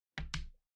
sfx_s04_碰牌.ogg